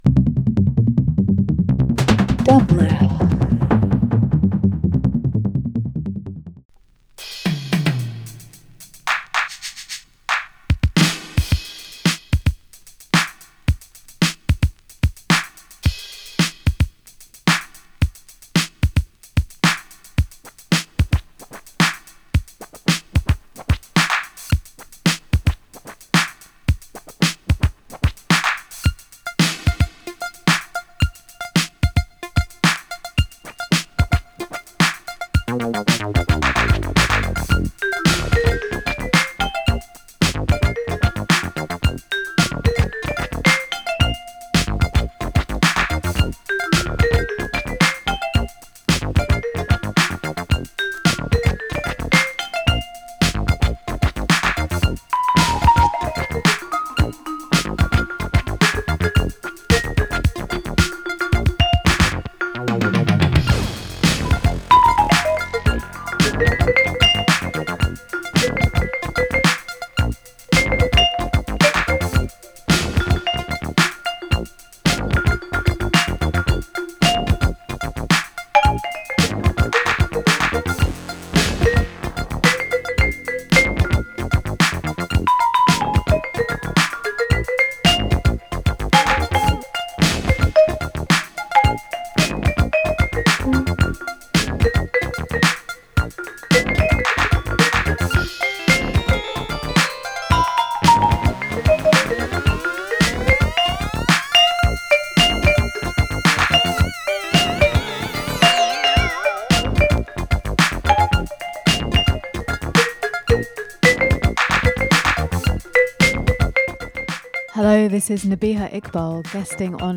Alternative Electronic Funk/Soul International Jazz Rock